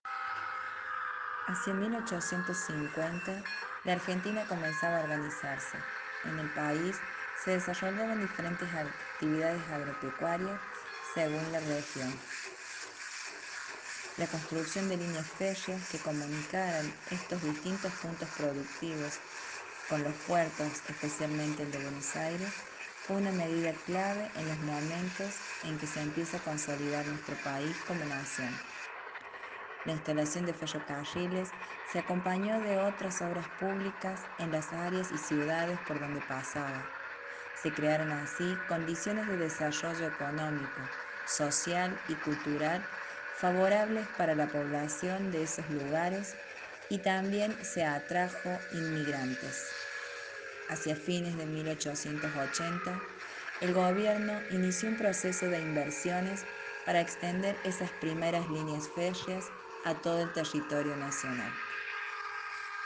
KIeb0Gdpxve_segundo-audio-tren-que-llega-a-ARgentina.ogg